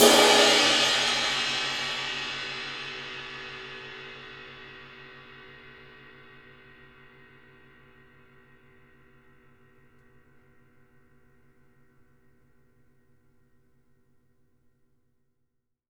Index of /90_sSampleCDs/Sampleheads - New York City Drumworks VOL-1/Partition A/KD RIDES
CRASH     -R.wav